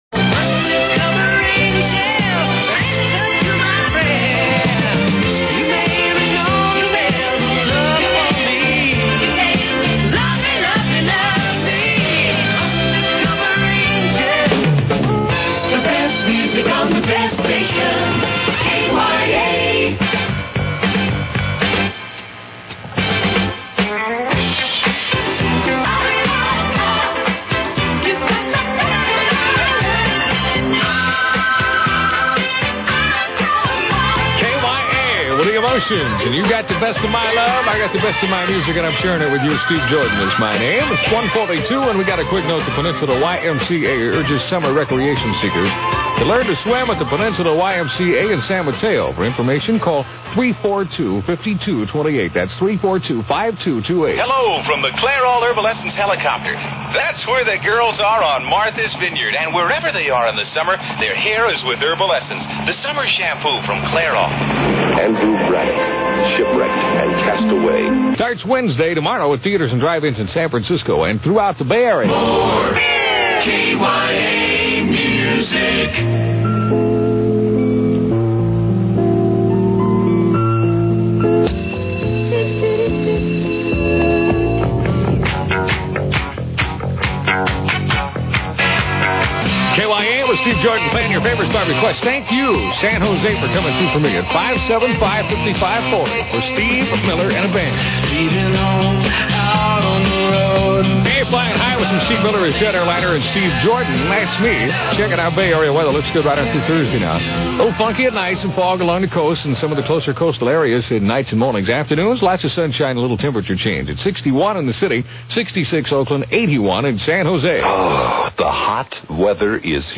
1260 KYA Aircheck Collection - Part Two: 1970s and 1980s | Bay Area